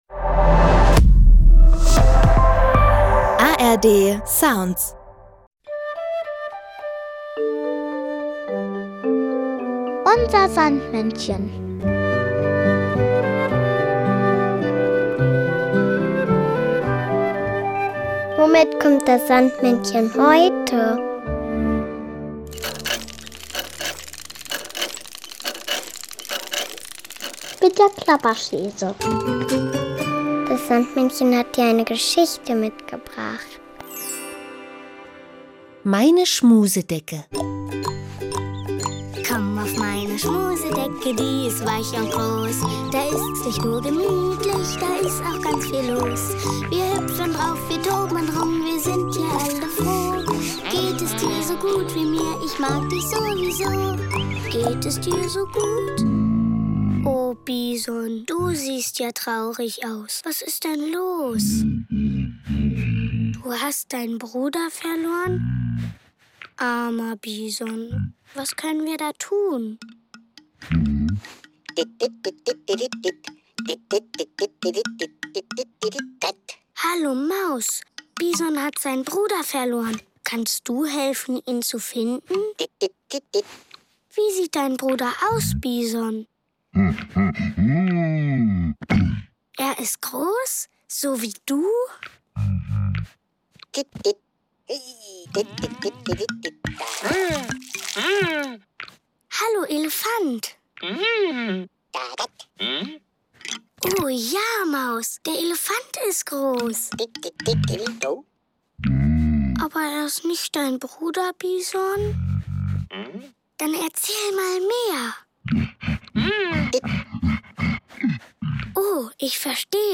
mitgebracht, sondern auch noch das Kinderlied "Brüderchen, komm